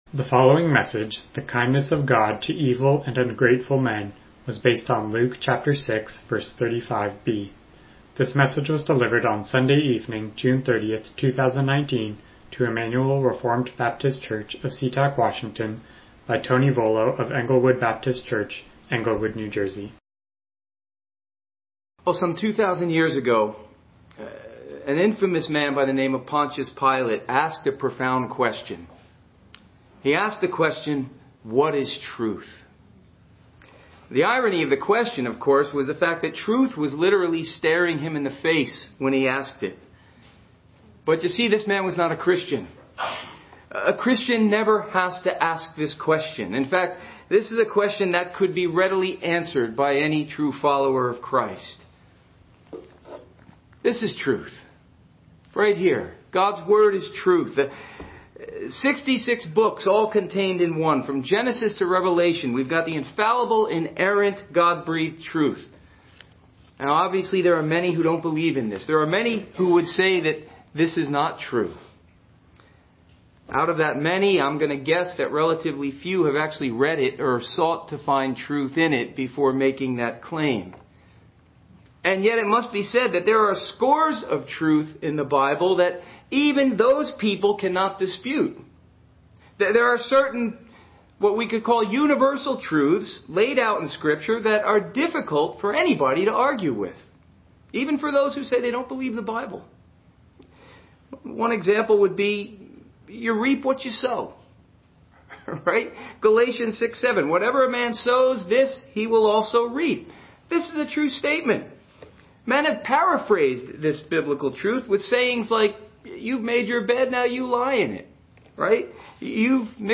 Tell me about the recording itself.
Luke 6:35 Service Type: Evening Worship « Paul’s Conflict of Two Natures Deuteronomy